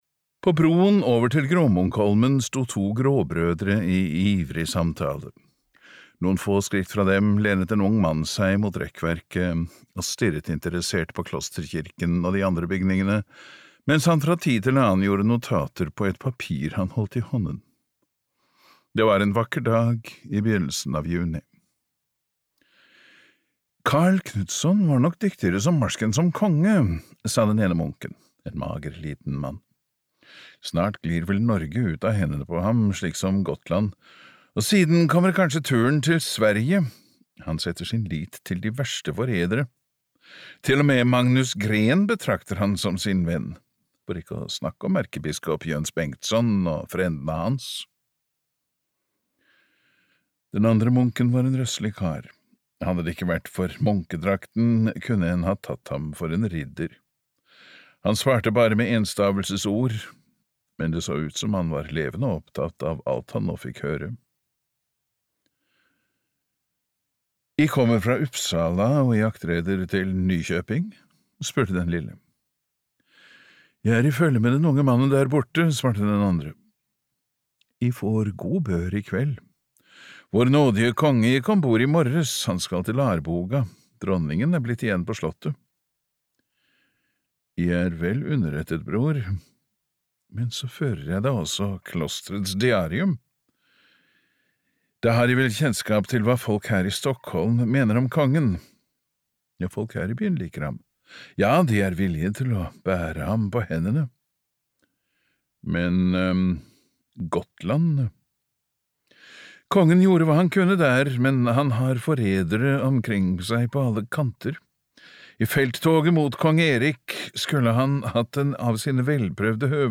Kongekronen (lydbok) av Carl Georg Starbäck